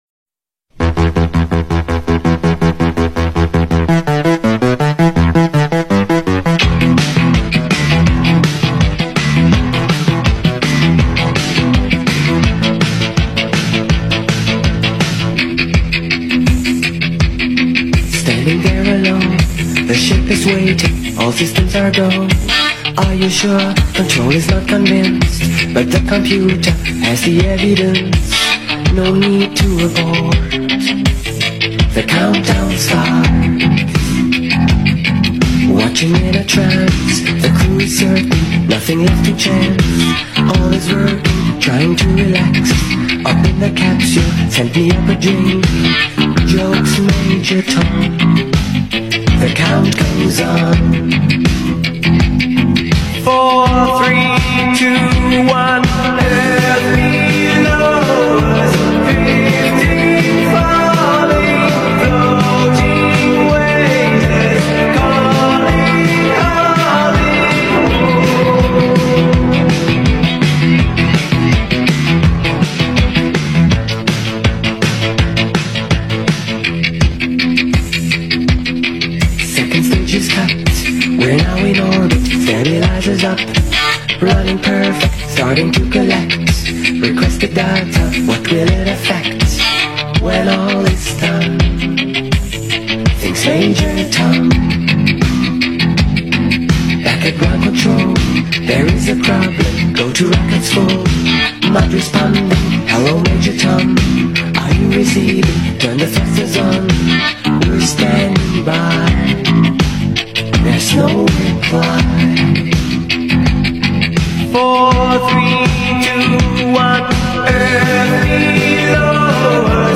Jamming to the music on the radio 📻 AI-GENERATED style